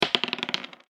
サイコロ・ダイス | 無料 BGM・効果音のフリー音源素材 | Springin’ Sound Stock
大きいシングルダイス2.mp3